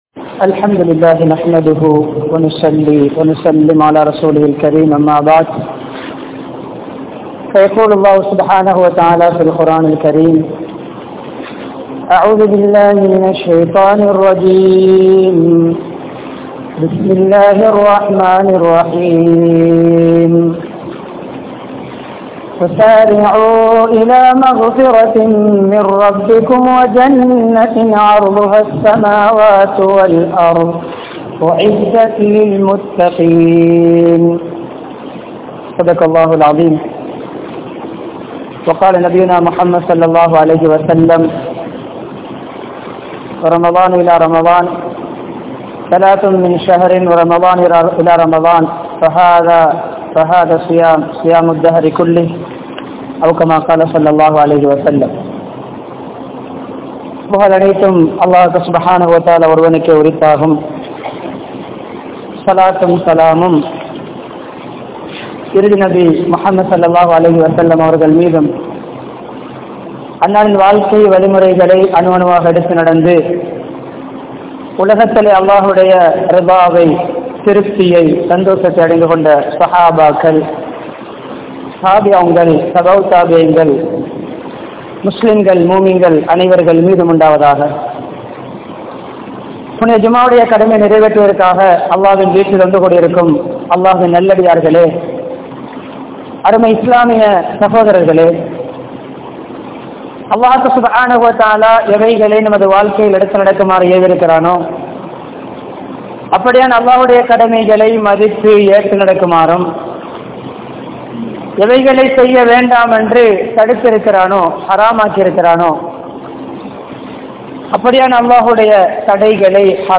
Ramalaanai Vara Veatpoam (ரமழானை வரவேற்போம்) | Audio Bayans | All Ceylon Muslim Youth Community | Addalaichenai